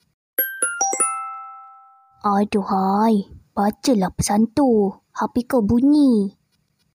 Genre: Nada dering imut